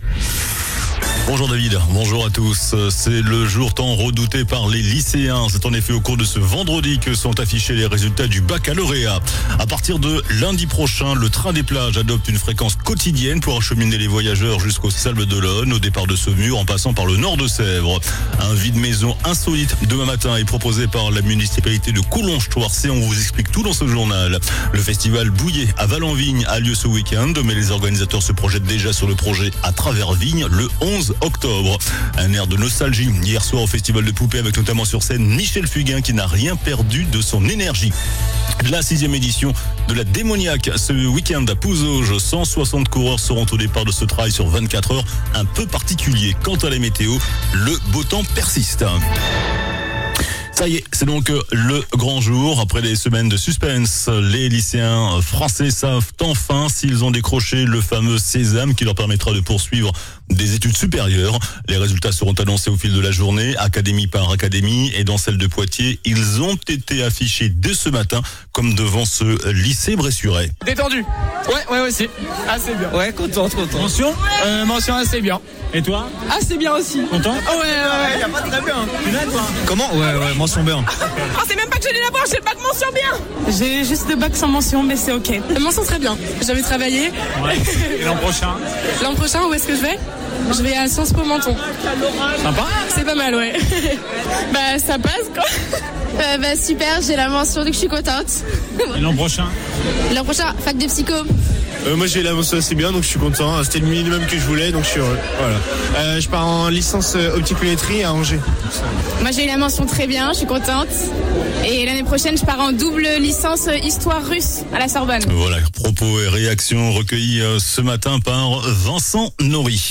JOURNAL DU VENDREDI 04 JUILLET ( MIDI )